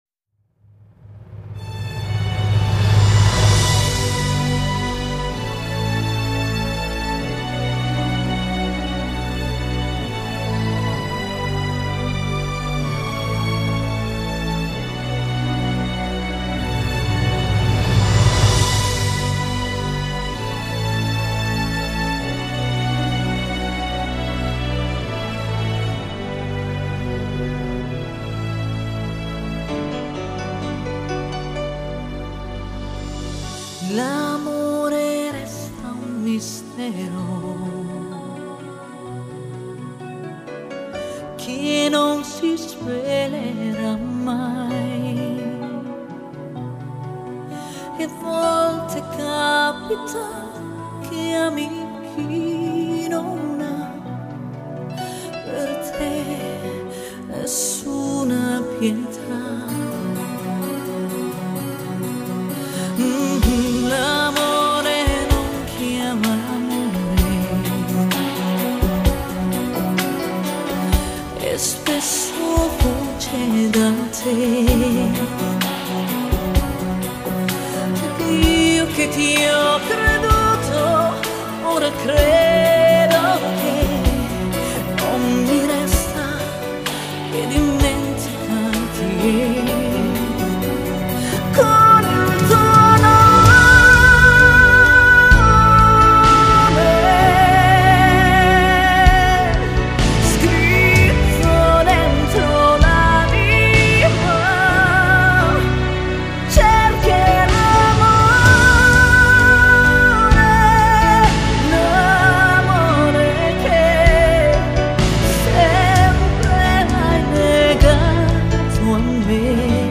簡介： 意大利女歌手，靈魂的歌者，把心唱成透明。